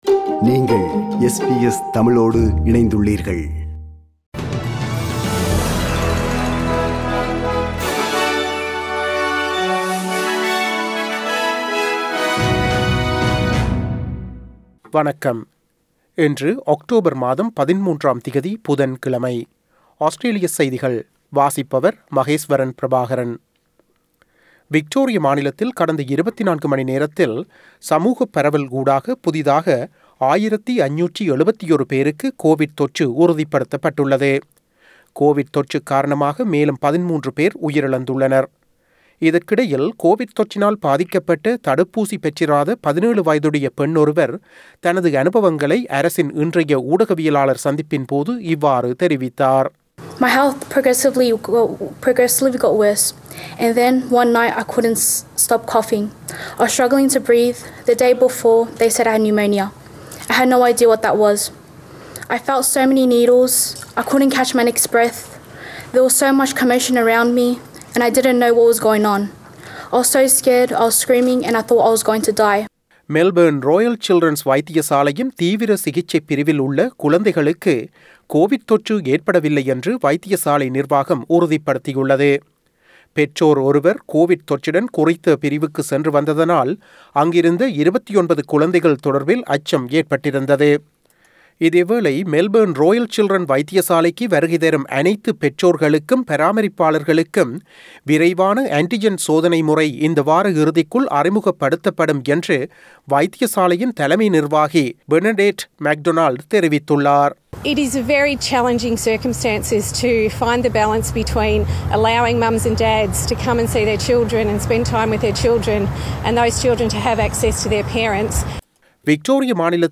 Australian news bulletin for Wednesday 13 October 2021.